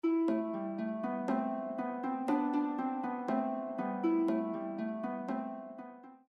Boute Selle – 6 string
It is a Provençal Christmas carol composed in the 17th century by Nicolas Saboly.